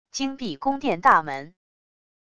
经闭宫殿大门wav音频